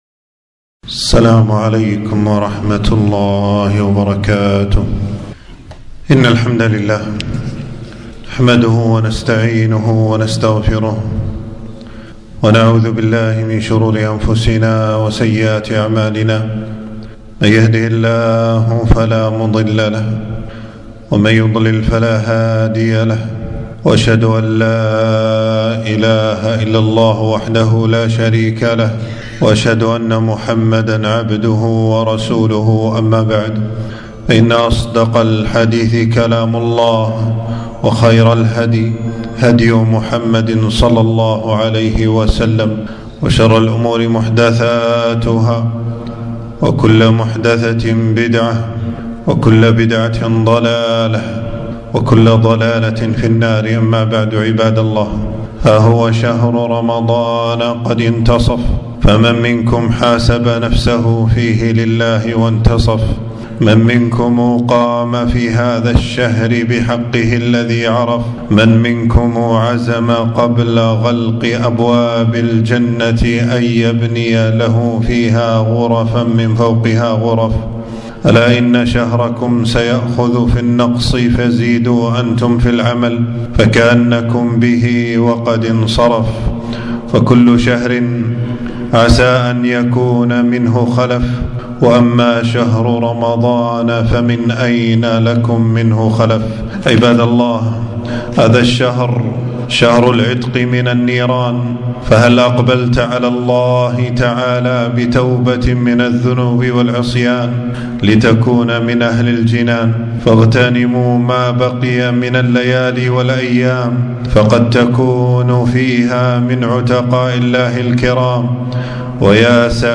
خطبة - اقترب النصف من رمضان فهل حاسبت نفسك